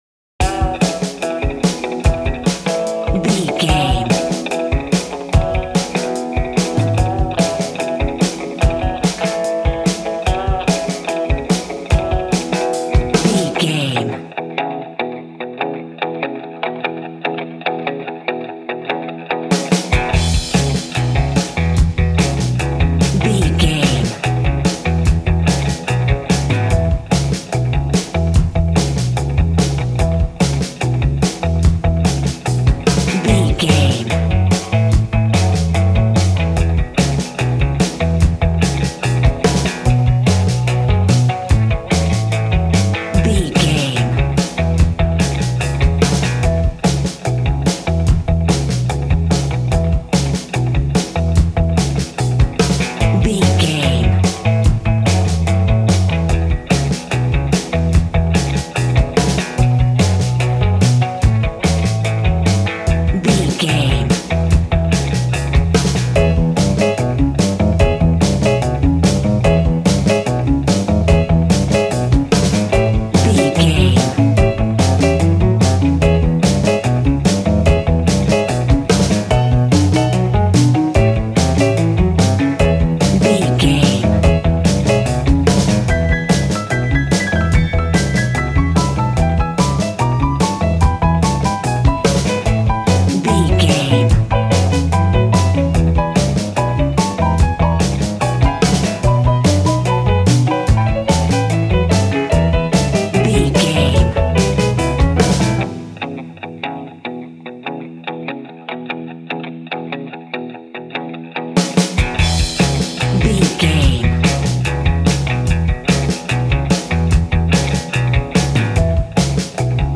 royalty free music
Ionian/Major
cheerful/happy
cool
double bass
drums
piano